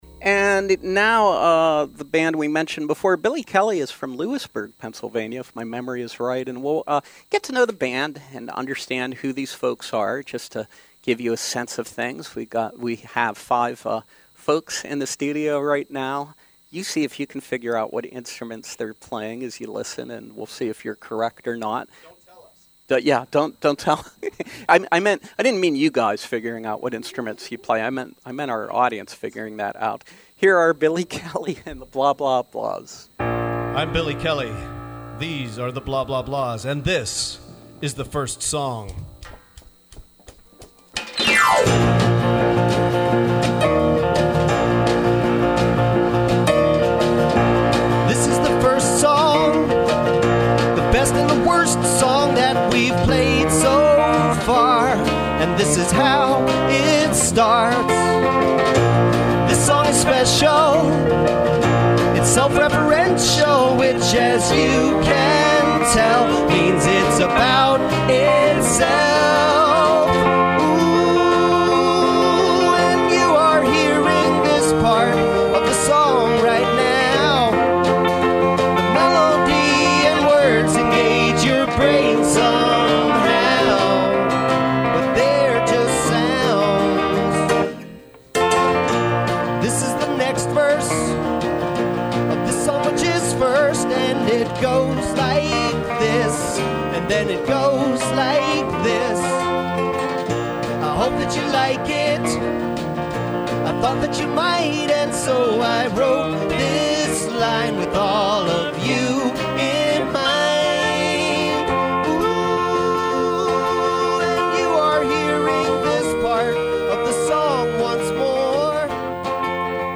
preview songs from their forthcoming CD